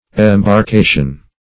Embarcation \Em`bar*ca"tion\, n.